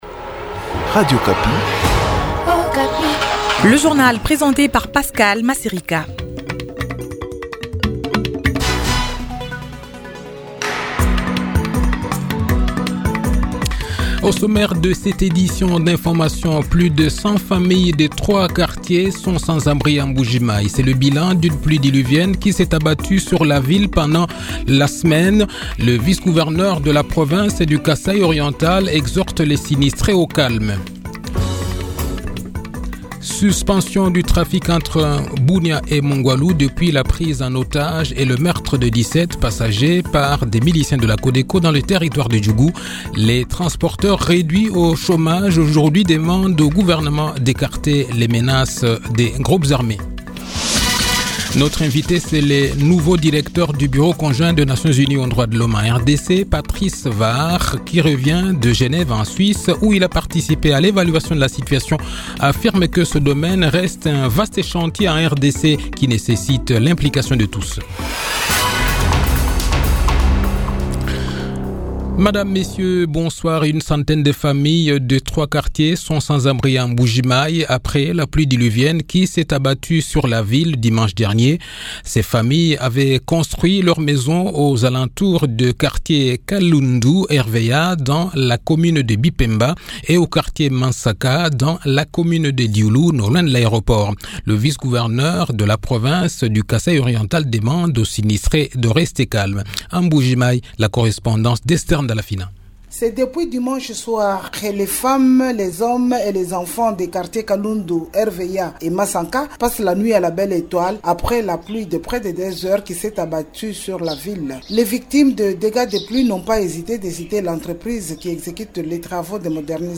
Le journal de 18 h, 5 avril 2023